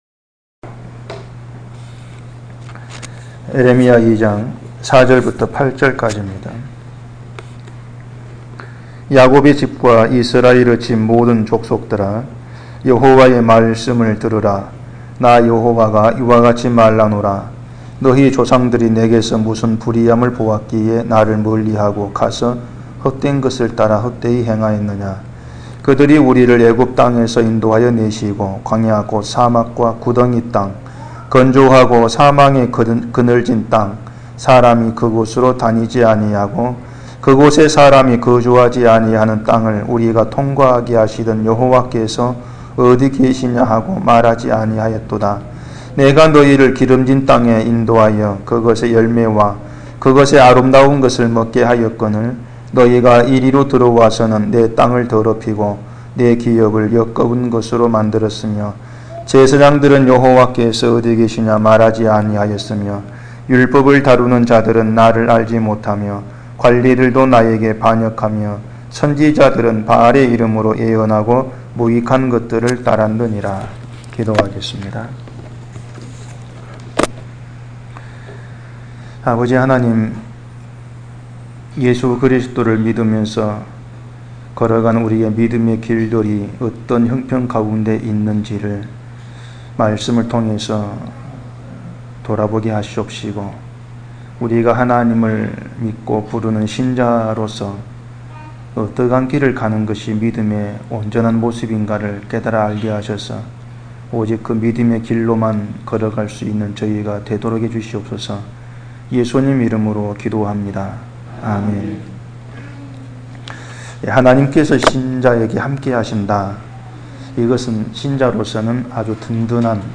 <설교> 하나님께서는 예레미야 선지자를 세워서 이스라엘의 잘못됨을 책망하십니다.